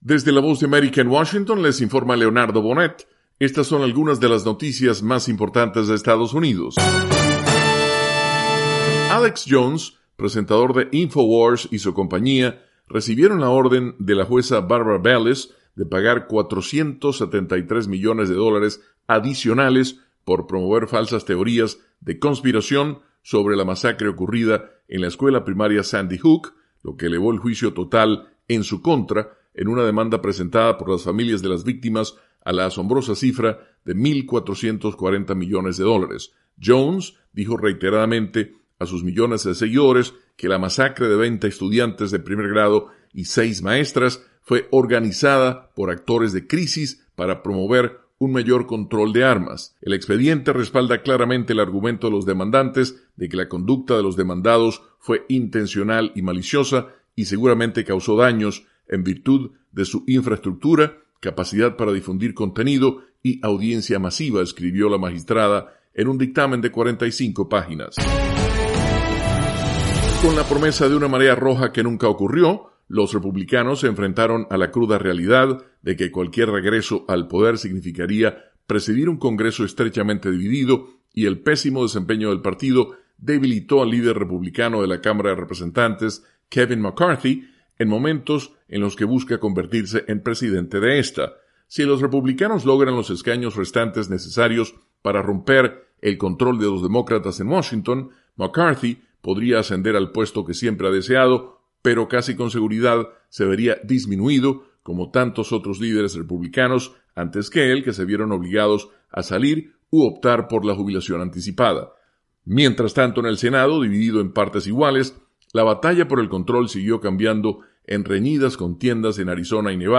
Resumen con algunas de las noticias más importantes de Estados Unidos